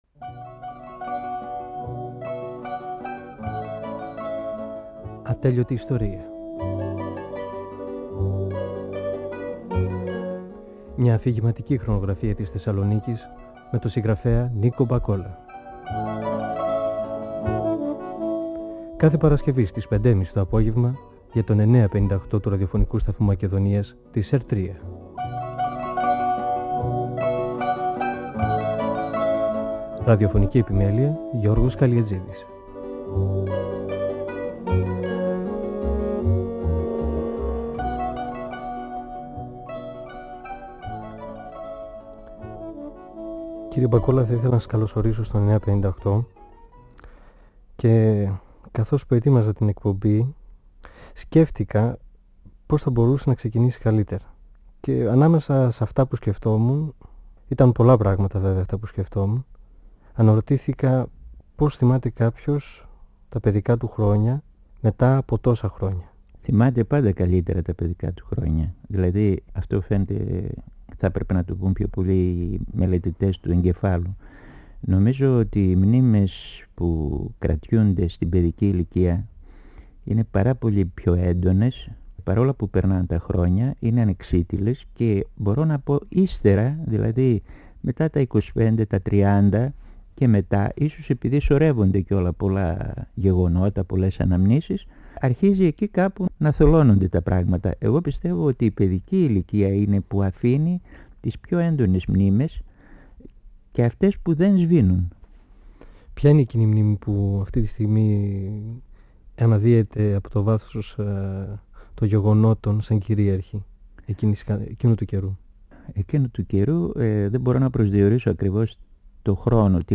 (Εκπομπή 1η) Ο πεζογράφος Νίκος Μπακόλας (1927-1999) μιλά για τα παιδικά του χρόνια στη γειτονιά του, την περιοχή της οδού 25ης Μαρτίου.
Η συνομιλία-συνέντευξη